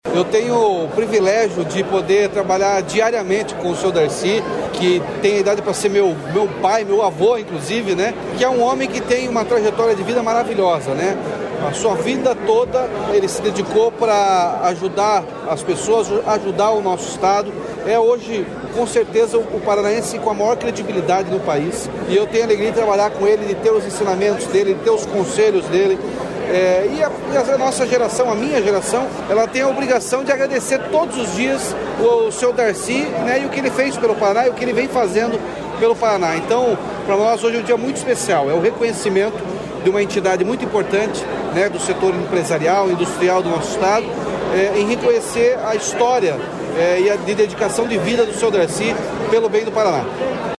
Sonora do governador Ratinho Junior sobre a homenagem de Darci Piana como Personalidade Aecic 2023 | Governo do Estado do Paraná